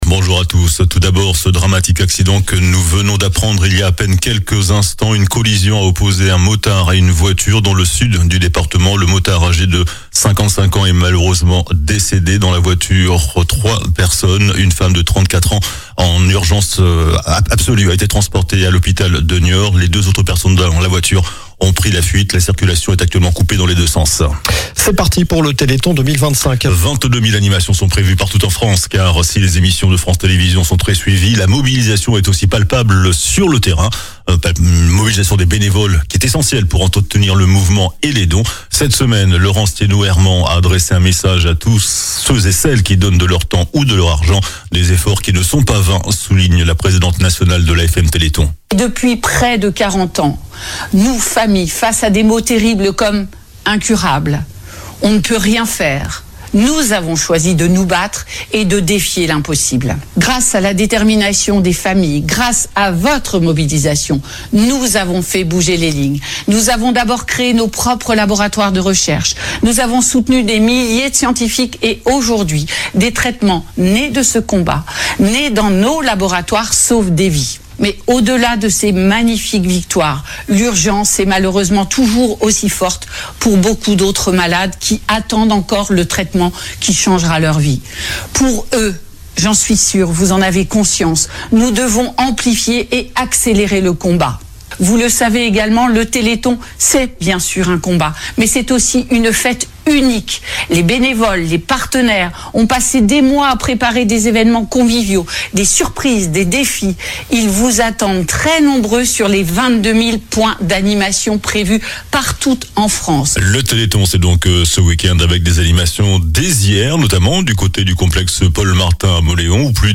Journal du samedi 6 décembre